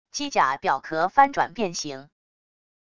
机甲表壳翻转变形wav音频